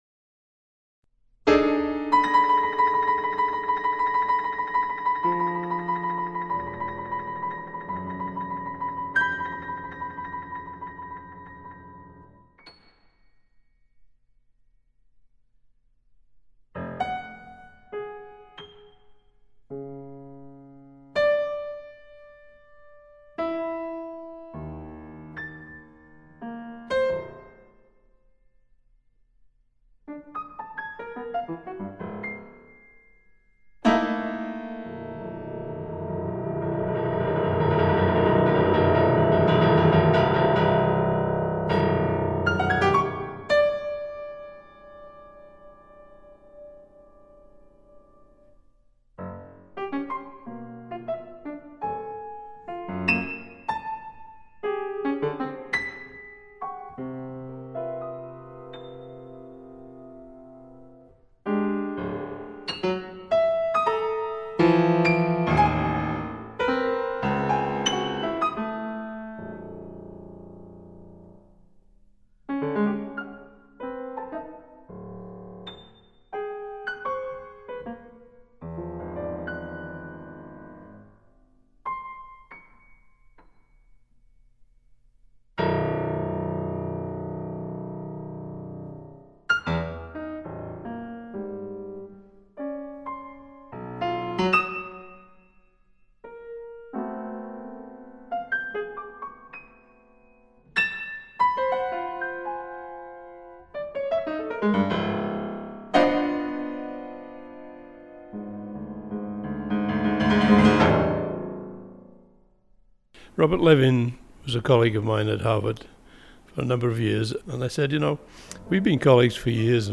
Pulitzer Prize winning composer, Bernard Rands shares his thoughts on his catalogue of over a hundred published works and recordings.